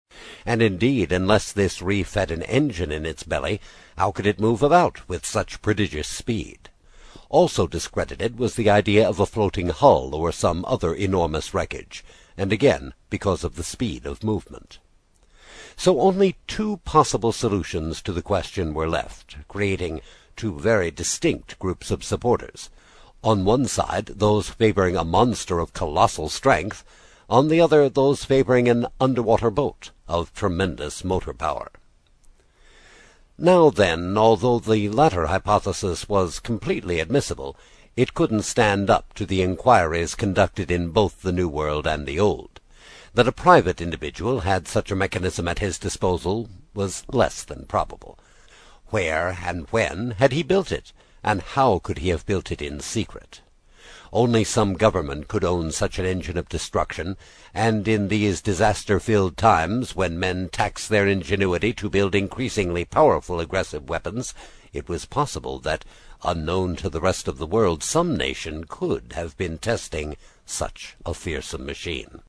英语听书《海底两万里》第13期 第2章 正与反(2) 听力文件下载—在线英语听力室
在线英语听力室英语听书《海底两万里》第13期 第2章 正与反(2)的听力文件下载,《海底两万里》中英双语有声读物附MP3下载